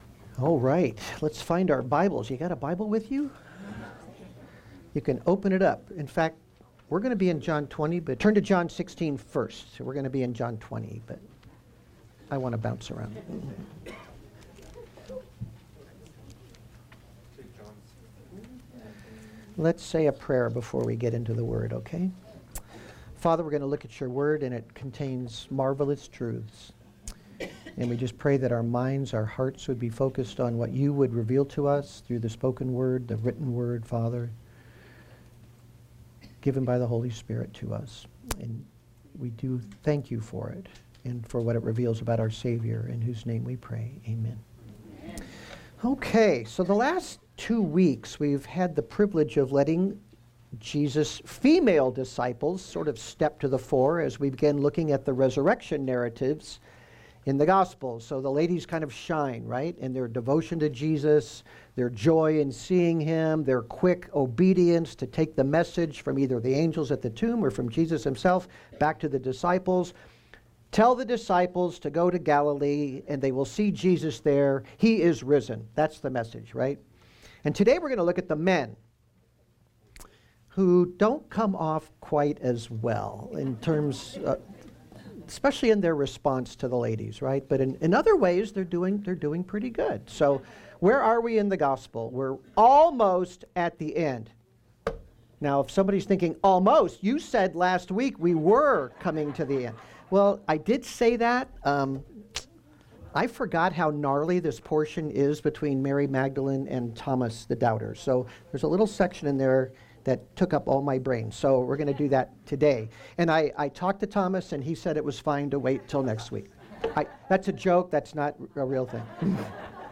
How is the Apostles’ disbelief in Jesus’ resurrection a help to us today? A question filled sermon from the Gospel of John